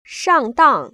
[shàng//dàng] 상땅